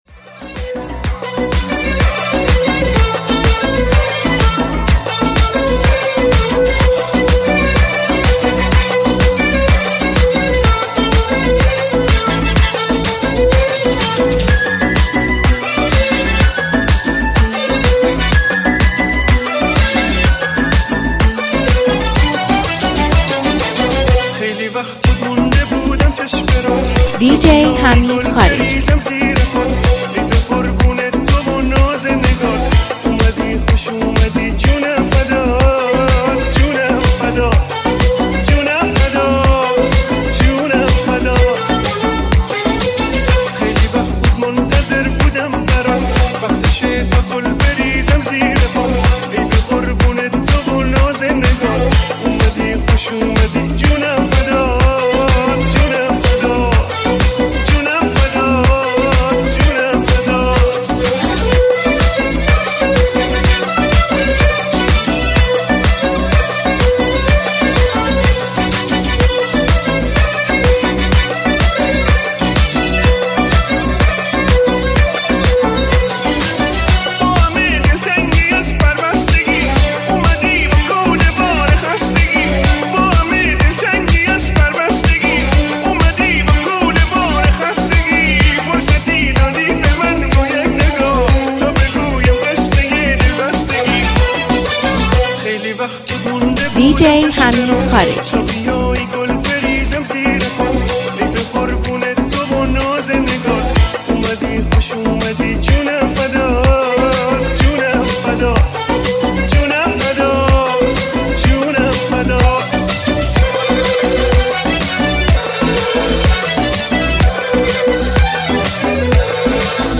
این میکس زیرخاکی و شاد رو به هیچ وجه از دست ندید!